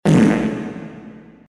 Fart reverb
fart-reverb.mp3